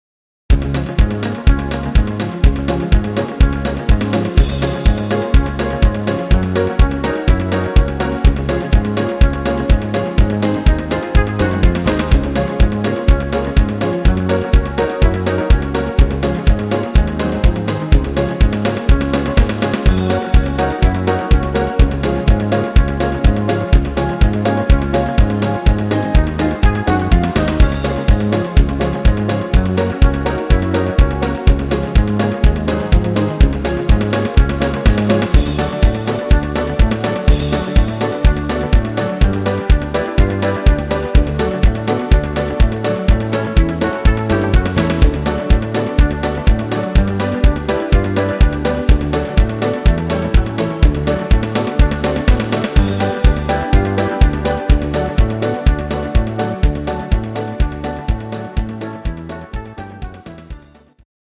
Instr. Saxophon